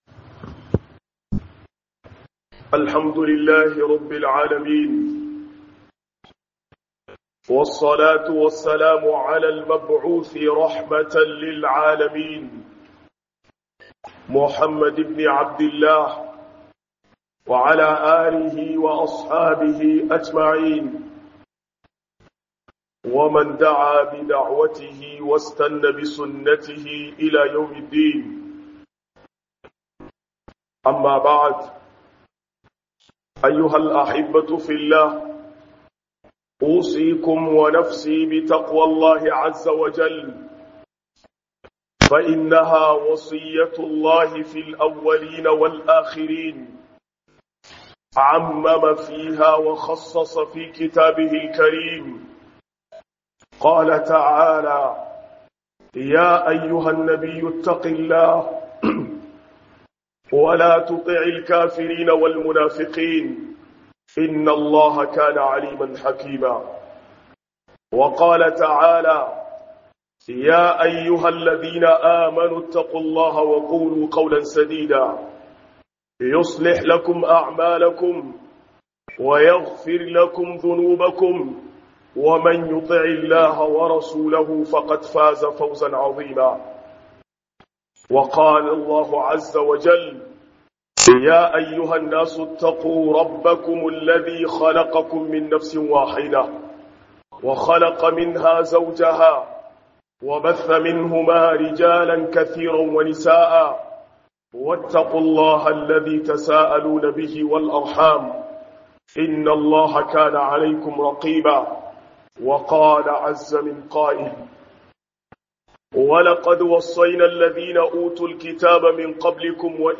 002-كل العلاقات تنقطع يوم القيامة إلا العلاقة الإيمان - خطبة الجمعة